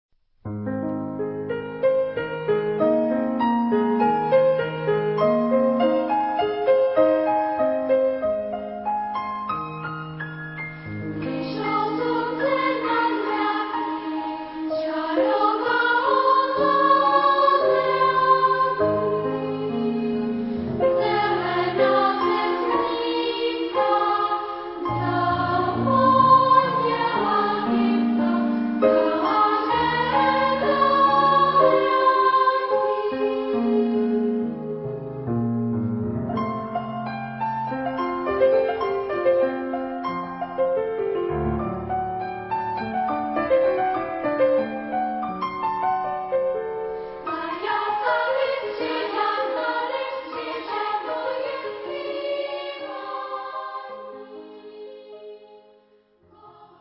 Type de choeur : SA  (2 voix égale(s) d'enfants )
Instruments : Piano (1)
Tonalité : la bémol majeur